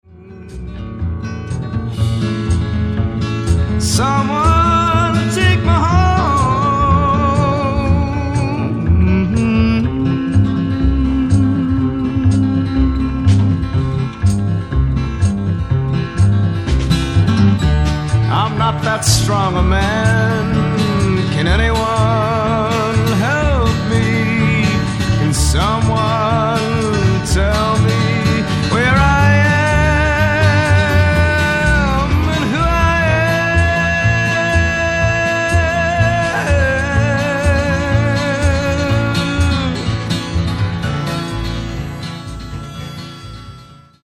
ジャズ、カントリー、ソウルなどを絶妙にブレンドして作られたサウンドはヒップかつクール、とにかく洗練されている。